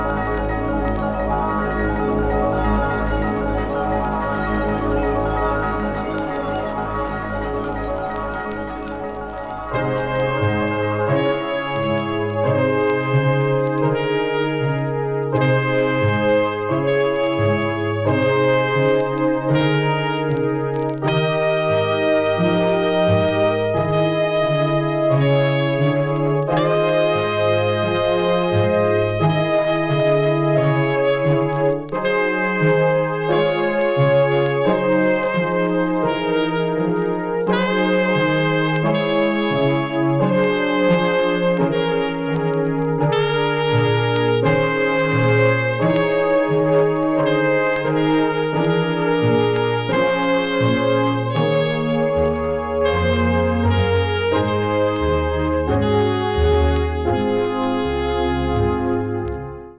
90s AMBIENT JAZZFUNK / FUSION 異世界 詳細を表示する